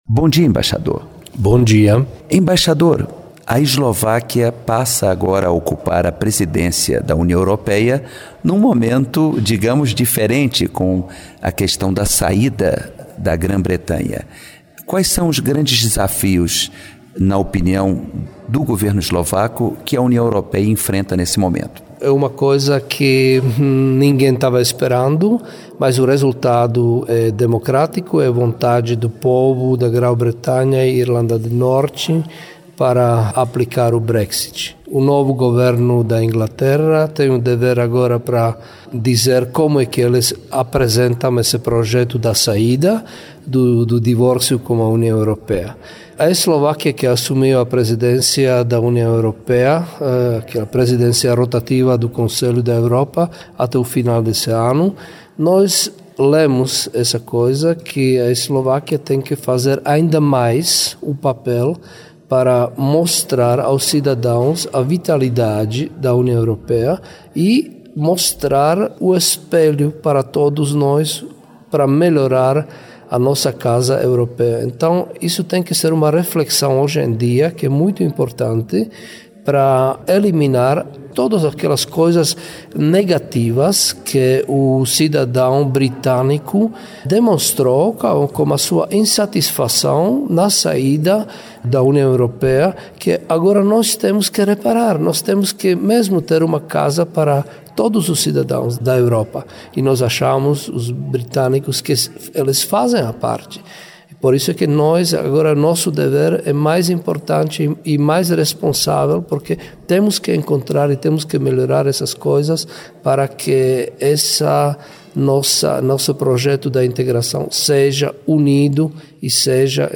entrevista o embaixador da Eslováquia no Brasil, Milan Cigan, sobre o cenário econômico da Europa após a saída do Reino Unido da União Europeia. A Eslováquia assumiu neste mês a presidência do bloco.